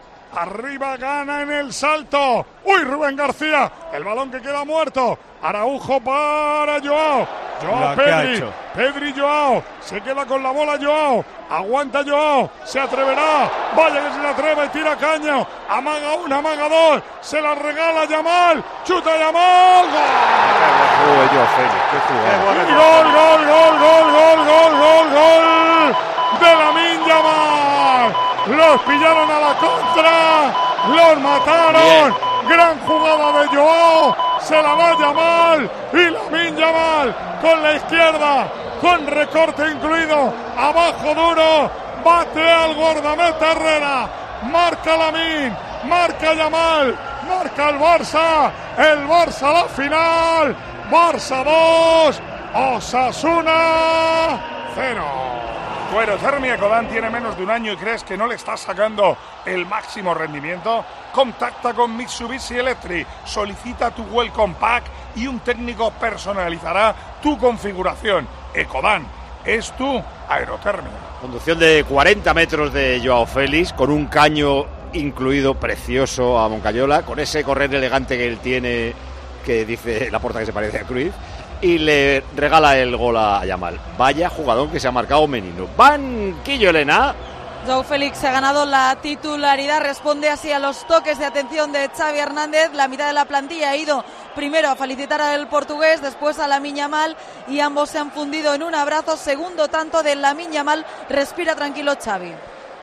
Así vivimos en Tiempo de Juego la retransmisión del Barcelona - Osasuna
Tiempo de Juego en el estadio Al-Awwal Park.